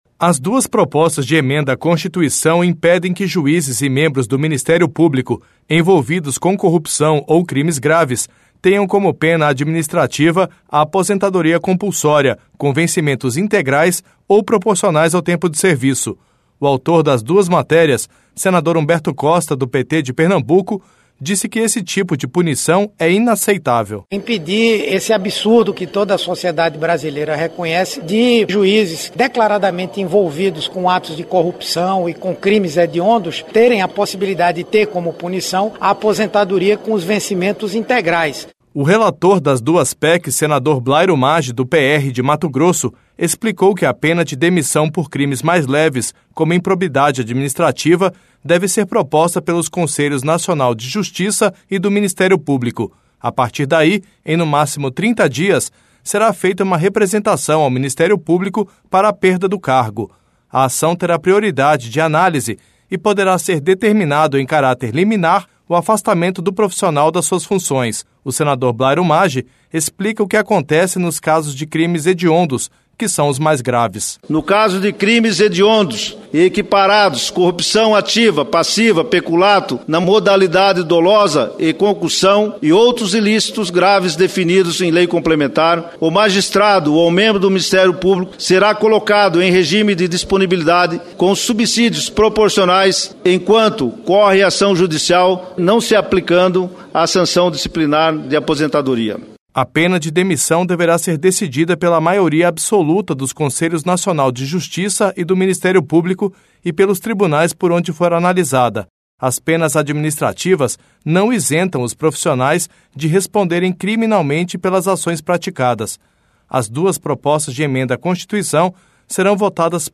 O autor das duas matérias, senador Humberto Costa, do PT de Pernambuco, disse que esse tipo de punição é inaceitável:
O senador Blairo Maggi explica o que acontece nos casos de crimes hediondos, que são os mais graves: